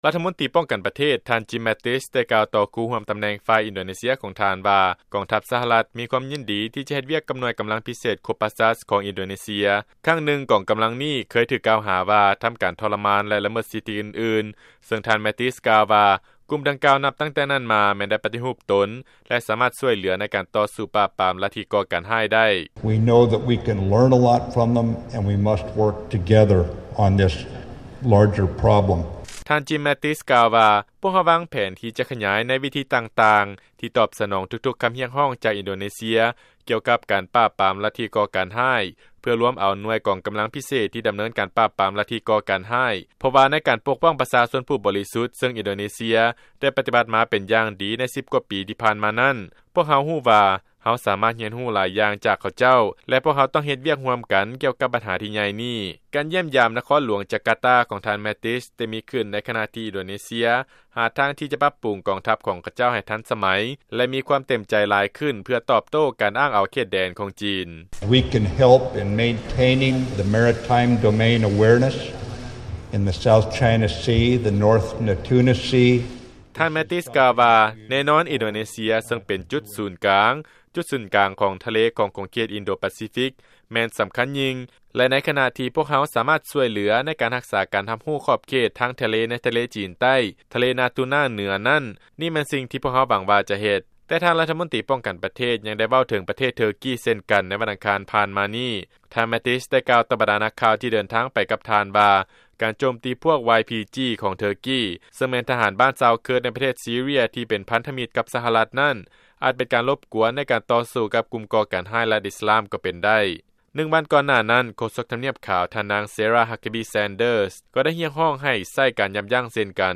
ຟັງລາຍງານ ທ່ານ ແມັດຕິສ ຄາດຫວັງ ຈະສັ້ງສາຍພົວພັນ ຢ່າງເລີກເຊິ່ງ ກັບ ອິນໂດເນເຊຍ ໃນການປາບາມ ລັດທິກໍ່ການຮ້າຍ